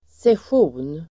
Uttal: [sesj'o:n]